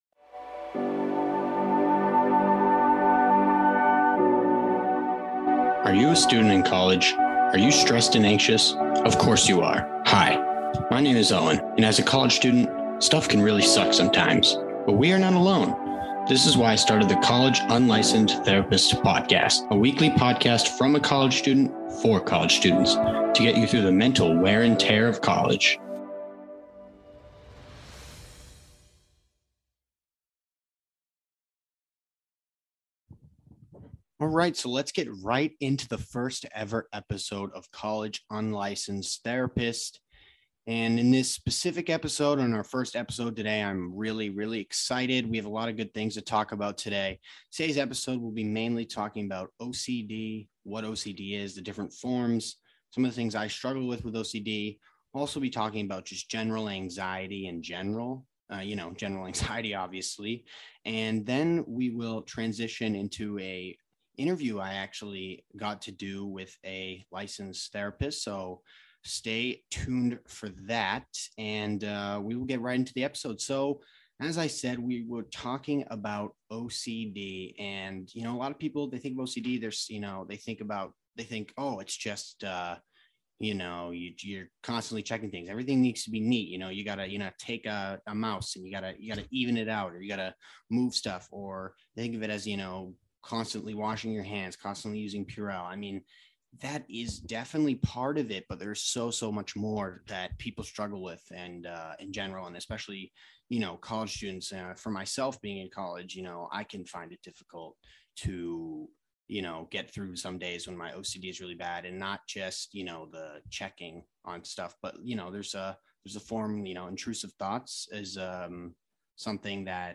1:00 to 6:00: Solo talk on general anxiety and OCD. 6:00 to 7:00 AD READ